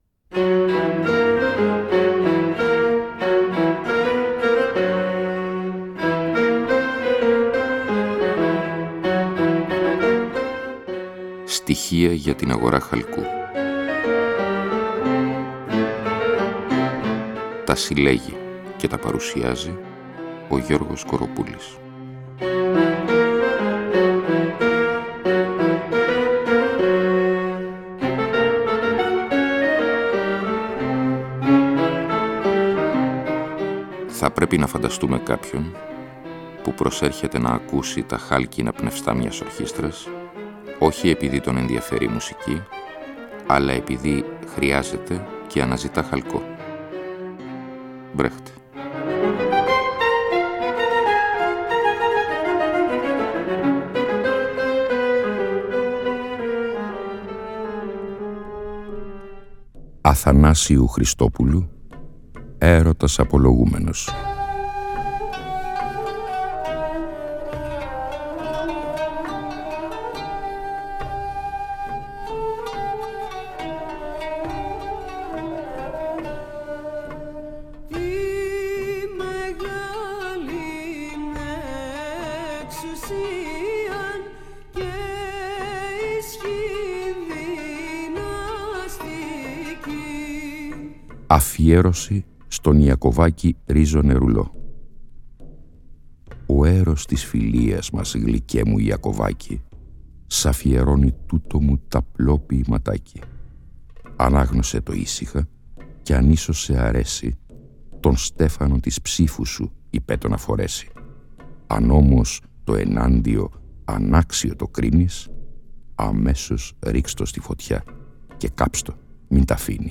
Με μουσική στίξη μια φαναριώτικη μισμαγιά για τον έρωτα, ακούμε σ’ αυτή και την επόμενη εκπομπή το “Ερωτας απολογούμενος”, έργο όψιμο (και εμφανώς επηρεασμένο από τον κρητικό ομοιοκατάληκτο δεκαπεντασύλλαβο τύπου “Απόκοπου”, εξού και θυμίζει πότε-πότε ο ρυθμός του τον σολωμικό “Κρητικό”) του Αθανάσιου Χριστόπουλου, του “Νέου Ανακρέοντα” και προδρόμου του Σολωμού.